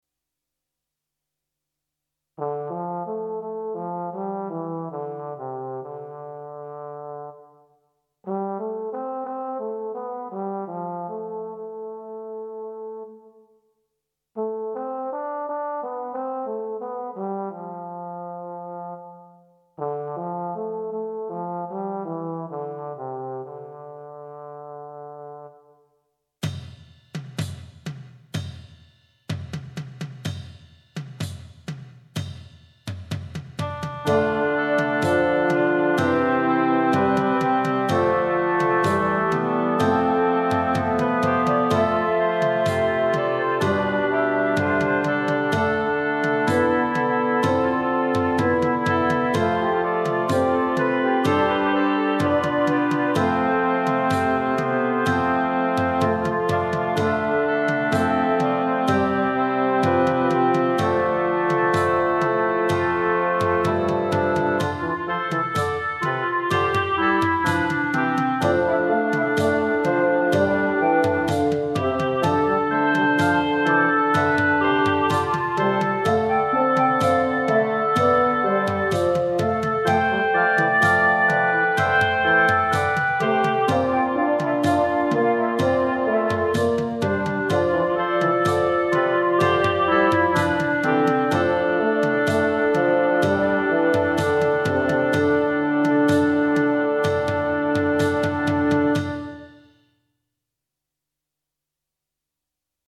Instrumentation: C, Bb, Eb, pno, Bass, Drums / perc.
Scored for 8 part flexible ensemble